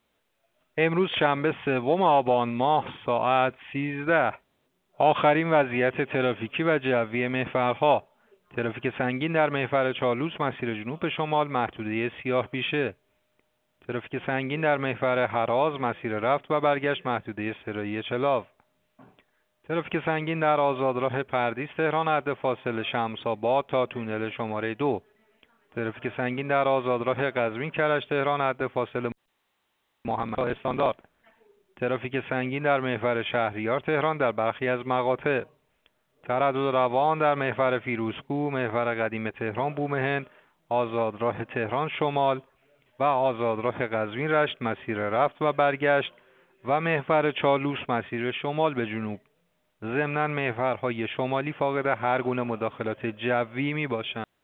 گزارش رادیو اینترنتی از آخرین وضعیت ترافیکی جاده‌ها ساعت ۱۳ سوم آبان؛